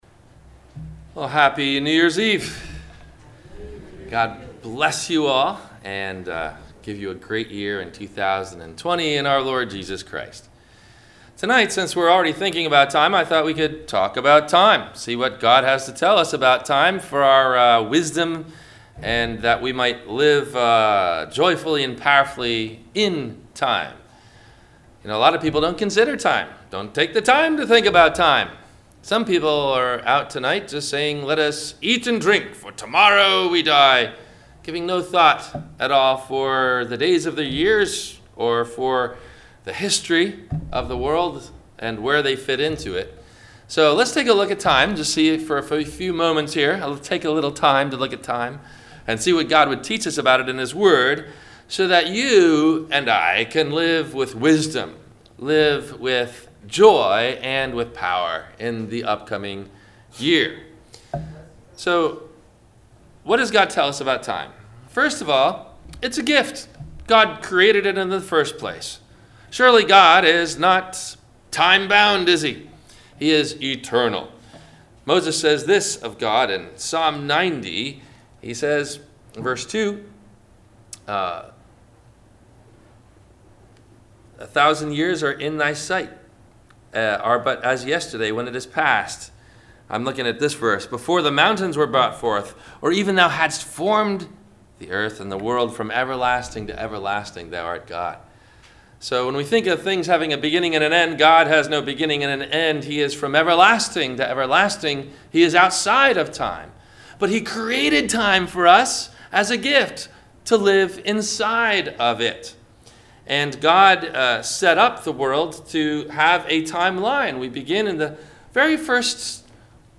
- New Years Eve - Sermon - December 31 2019 - Christ Lutheran Cape Canaveral